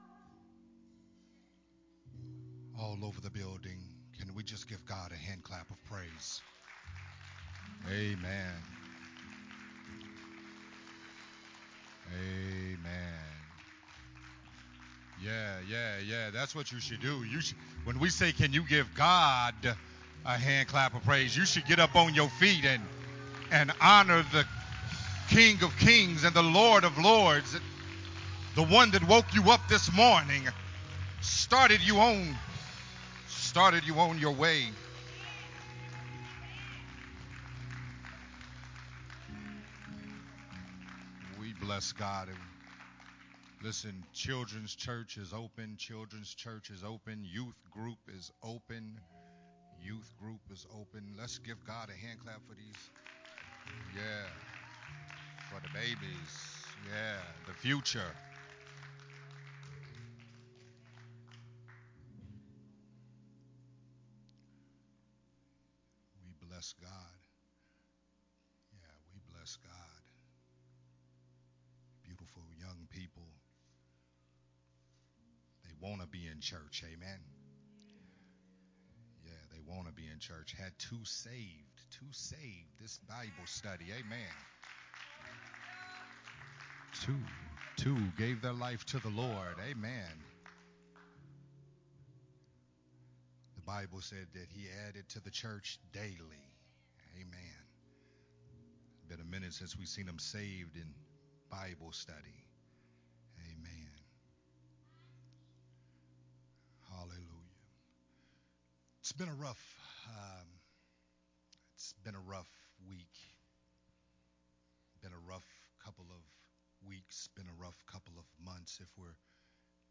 a sermon
recorded at Unity Worship Center